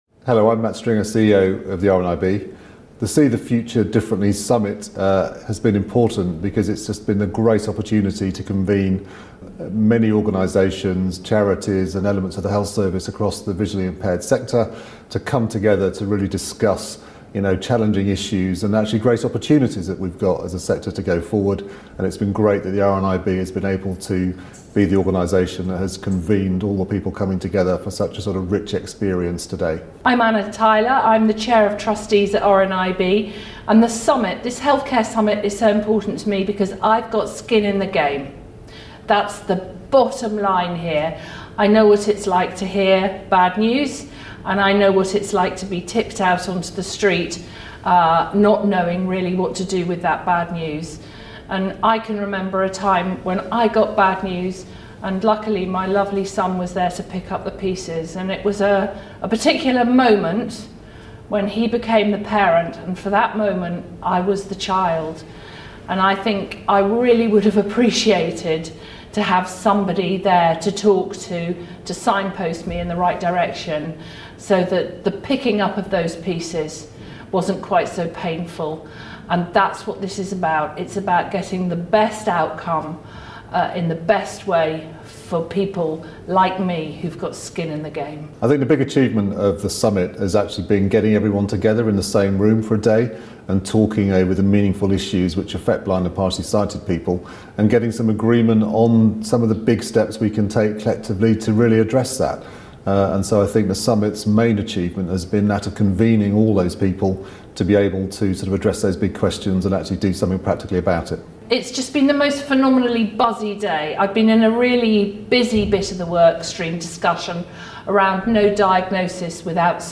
Interview
Audio taken from video interviews